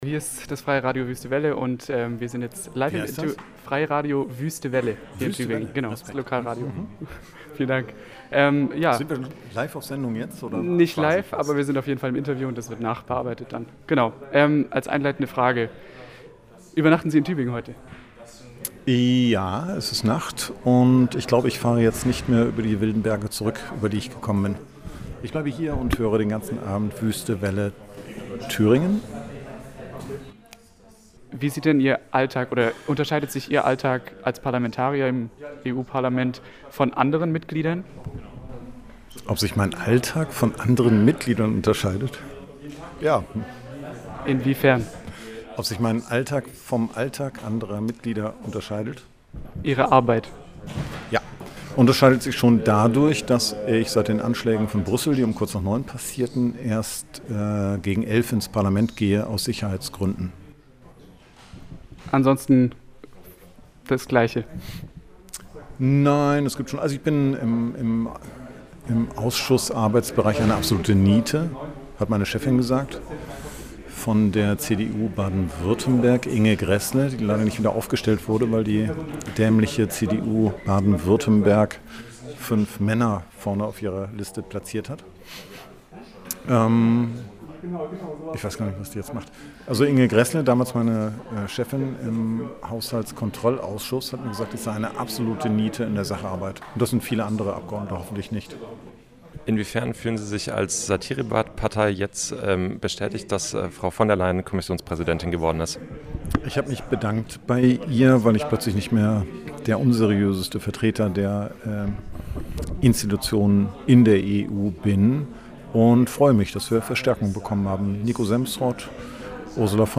Martin Sonneborn im Interview
73026_Interview_Sonneborn.mp3